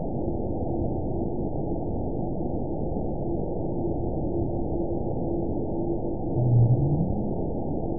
event 922852 date 04/19/25 time 22:58:47 GMT (1 month, 3 weeks ago) score 9.26 location TSS-AB02 detected by nrw target species NRW annotations +NRW Spectrogram: Frequency (kHz) vs. Time (s) audio not available .wav